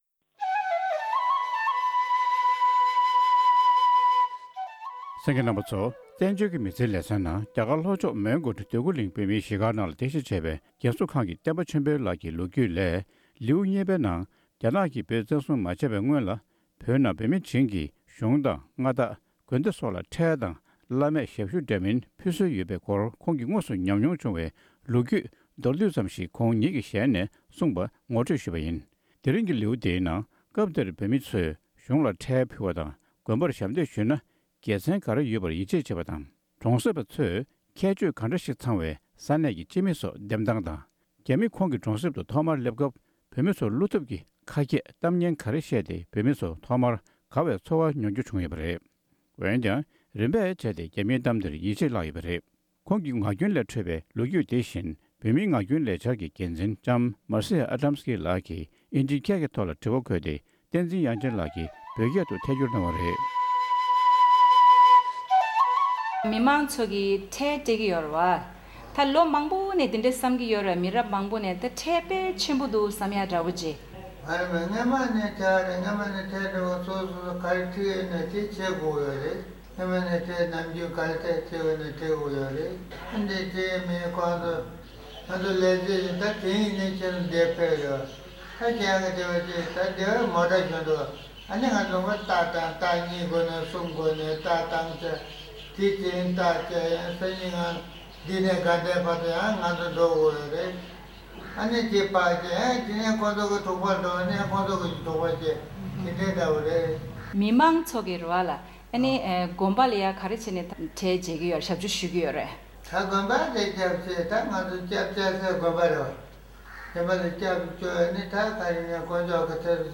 བགྲེས་པོའི་ངག་རྒྱུན་ལས་བོད་ཀྱི་ལོ་རྒྱུས།